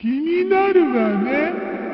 File File history File usage Metadata Dev-SMW2-Ver0-sound-back_up-sdki_sbn.ogg  (Ogg Vorbis sound file, length 1.9 s, 62 kbps) Summary This file is an audio rip from a(n) SNES game.